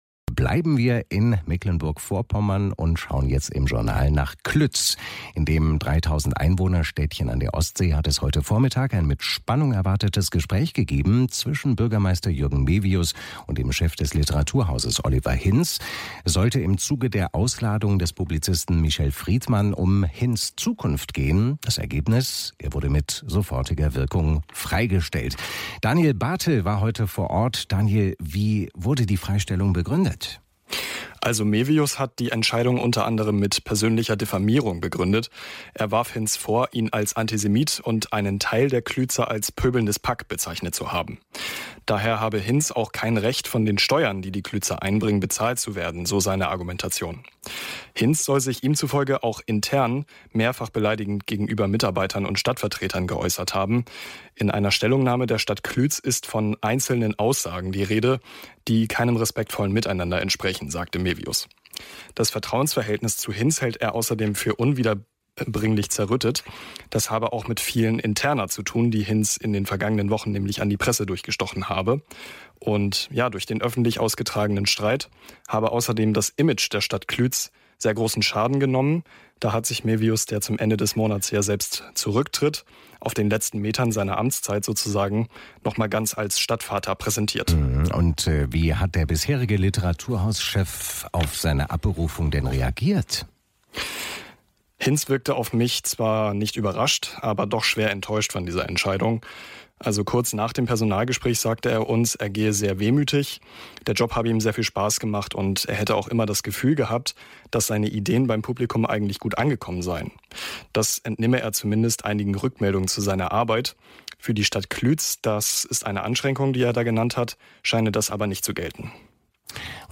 NDR Kultur, Journal, Bericht